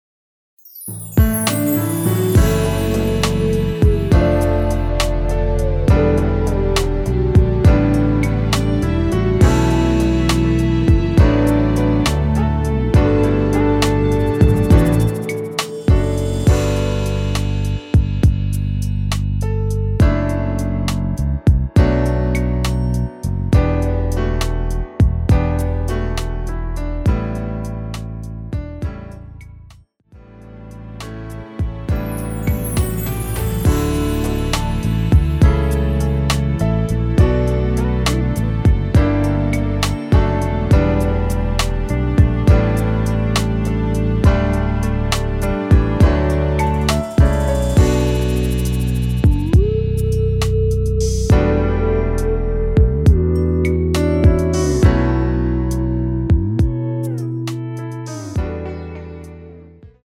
음정은 반음정씩 변하게 되며 노래방도 마찬가지로 반음정씩 변하게 됩니다.
앞부분30초, 뒷부분30초씩 편집해서 올려 드리고 있습니다.
중간에 음이 끈어지고 다시 나오는 이유는
곡명 옆 (-1)은 반음 내림, (+1)은 반음 올림 입니다.